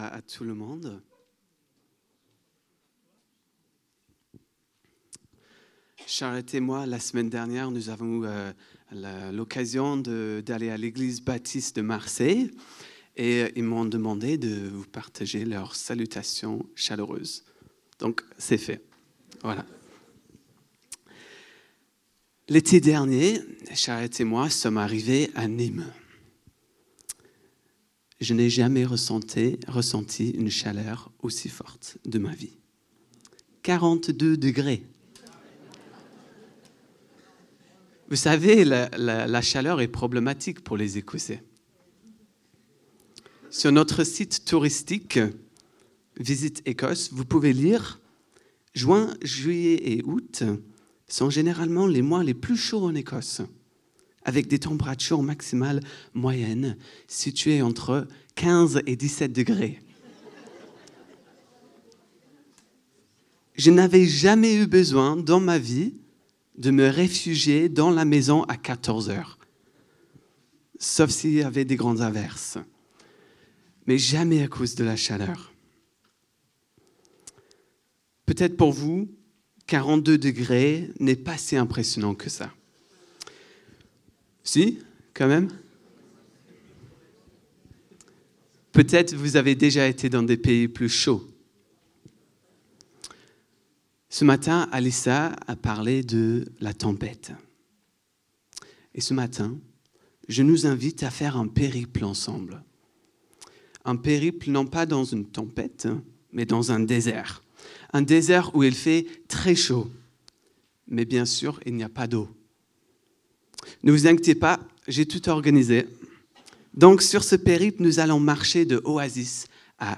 Culte du dimanche 08 mars 2026, prédication